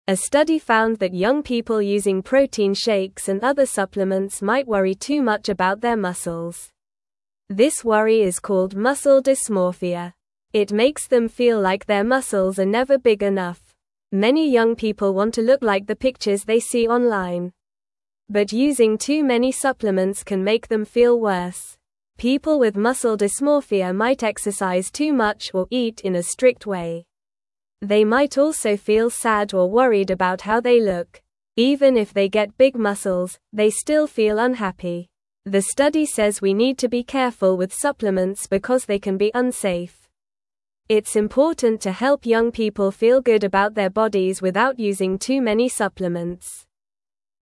Normal
English-Newsroom-Beginner-NORMAL-Reading-Worrying-About-Muscles-Can-Make-You-Unhappy.mp3